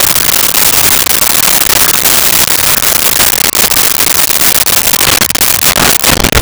Gorilla Snarl 02
Gorilla Snarl 02.wav